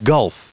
Prononciation du mot gulf en anglais (fichier audio)
Prononciation du mot : gulf